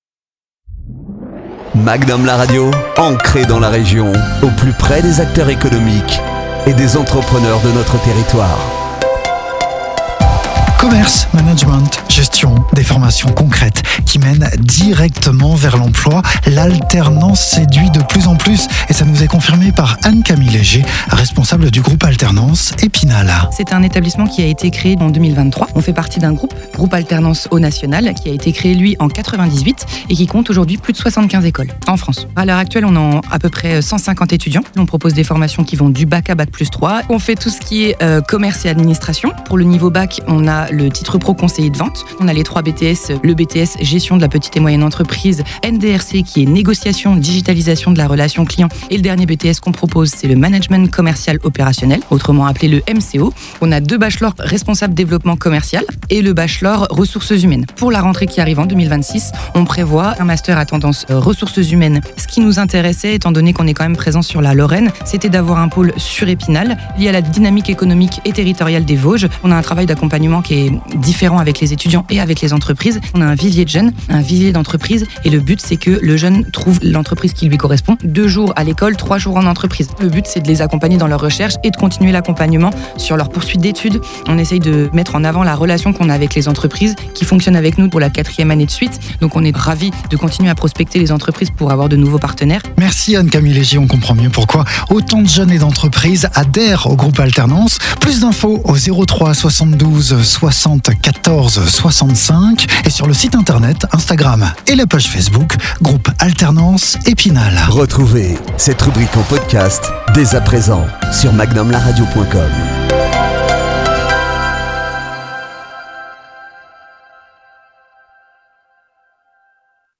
Publireportage